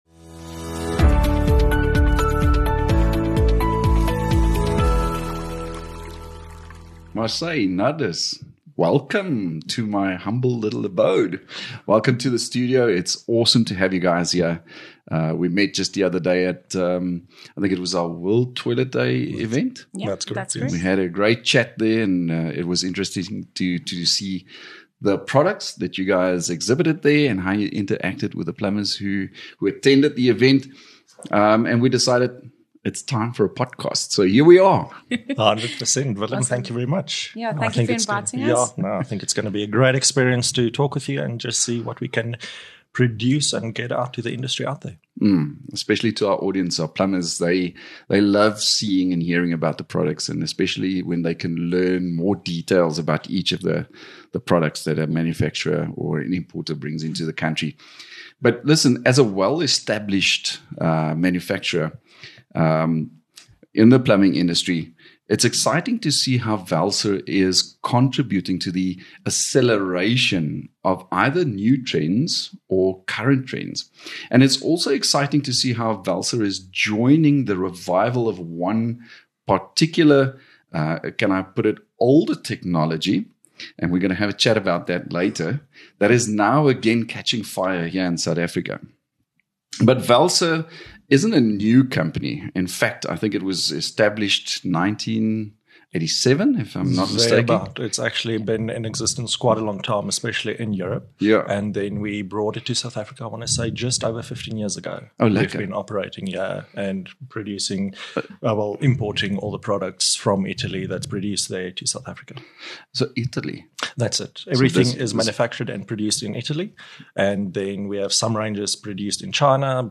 The Plumbing Industry Registration Board (PIRB) is a trusted professional body, as recognised by the South African Qualifications Authority (SAQA), that works proactively to promote better plumbing practices in South Africa. In these podcast episodes we will have interesting and informative conversations with industry experts, PIRB personnel, and also with other invited guests, with the intent to serve our registered plumbers through yet another exciting and convenient platform.